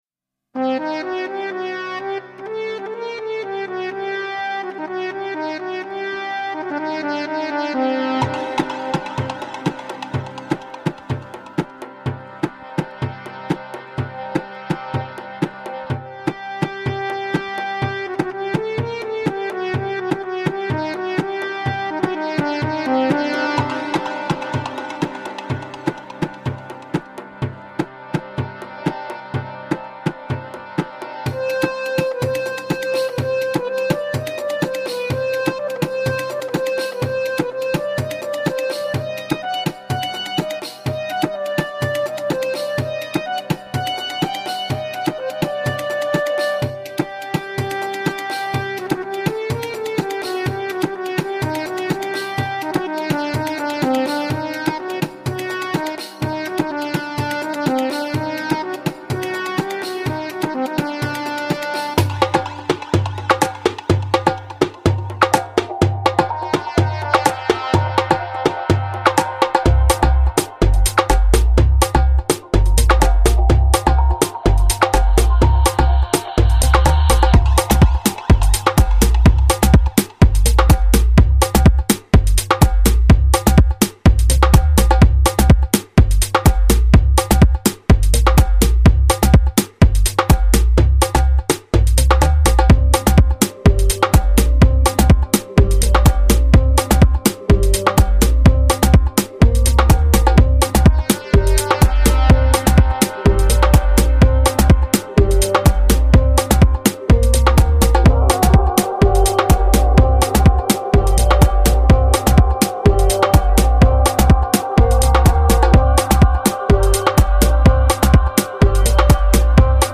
【销魂鼓点】《Pacific Drums》 激动社区，陪你一起慢慢变老！